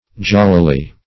jollily - definition of jollily - synonyms, pronunciation, spelling from Free Dictionary Search Result for " jollily" : The Collaborative International Dictionary of English v.0.48: Jollily \Jol"li*ly\, adv. In a jolly manner.